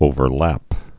(ōvər-lăp)